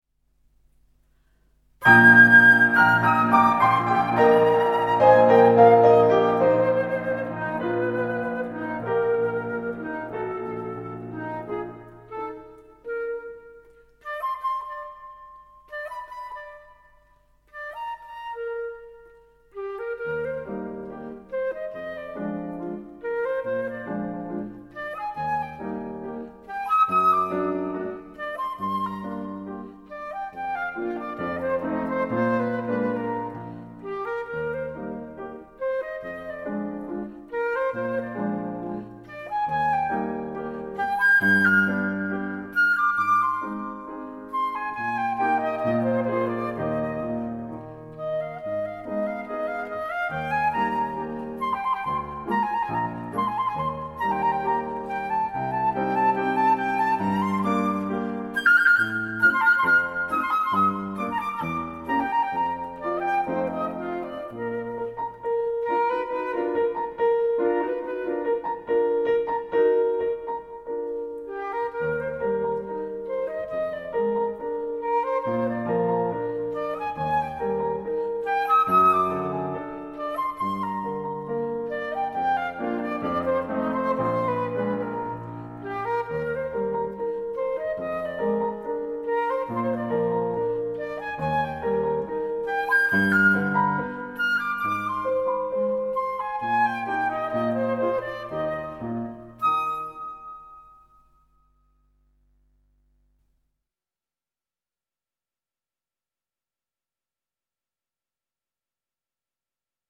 小品式的音乐，长笛、钢琴和竖琴的组合
录音的音色也是赏心悦耳
它的音色柔美，金属光泽中透出一种人性化的特质
这三件乐器的录音效果则非比寻常，声部的平衡感和音质的透彻感绝可媲美世界同类制作。